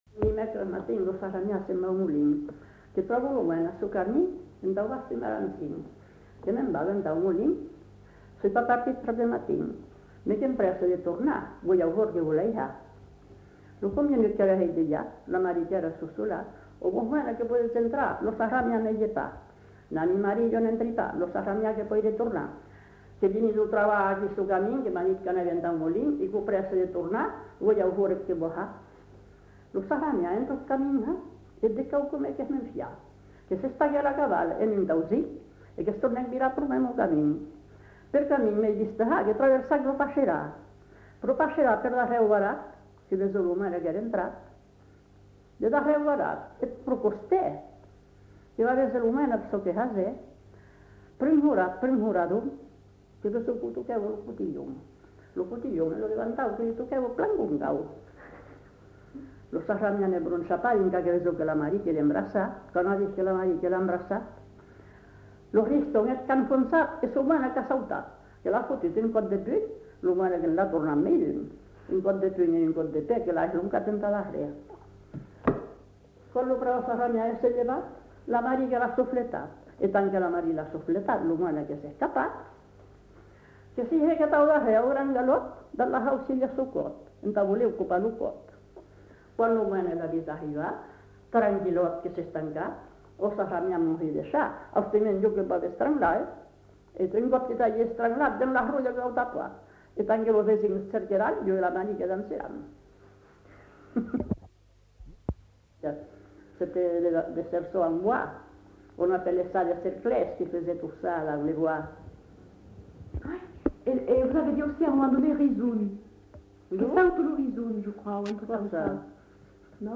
[Brocas. Groupe folklorique] (interprète)
Aire culturelle : Marsan
Genre : chant
Effectif : 1
Type de voix : voix de femme
Production du son : récité